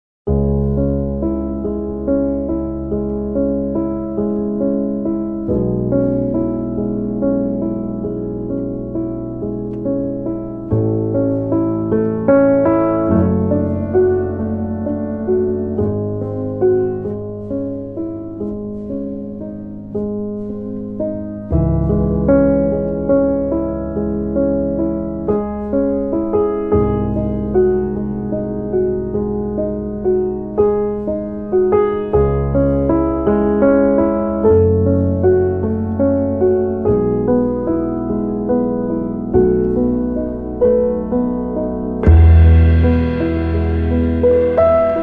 piano
bass
drums
美しきクラシックのスタンダード・チューンをス インギンなジャズのフィーリングで力強く、そして優雅にプレイ！